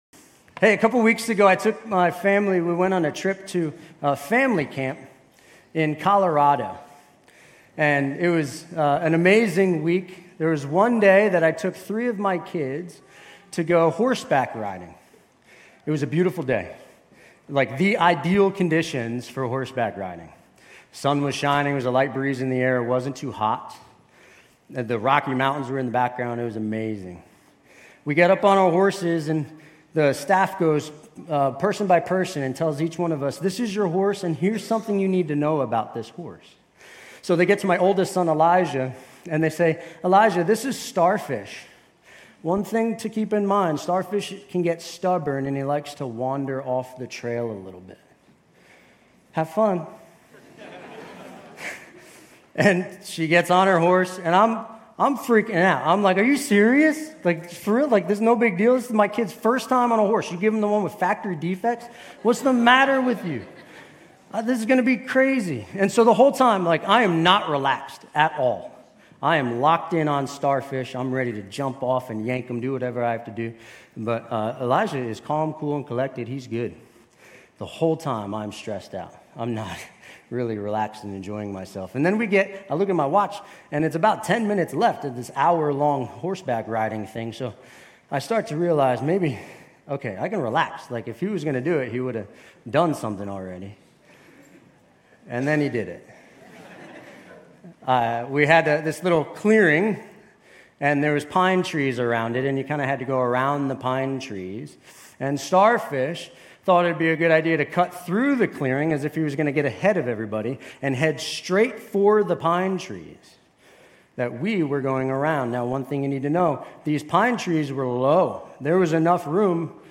Grace Community Church University Blvd Campus Sermons Psalm 77 - Lament Jul 01 2024 | 00:31:44 1x 00:00 / 00:31:44 Subscribe Share RSS Feed Share Link Embed